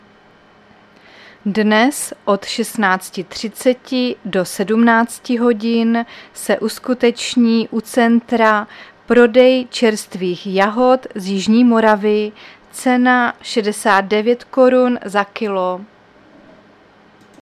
Záznam hlášení místního rozhlasu 29.6.2023